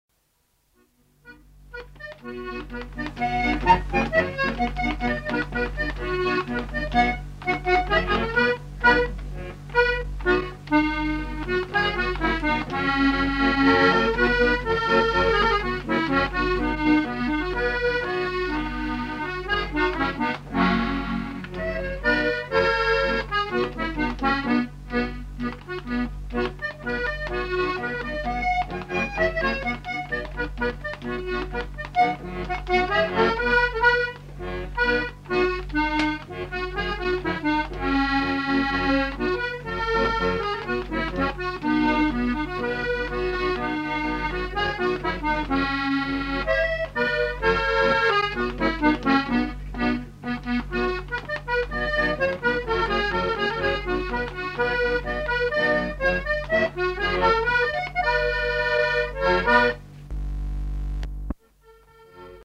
Aire culturelle : Savès
Genre : morceau instrumental
Instrument de musique : accordéon diatonique
Danse : quadrille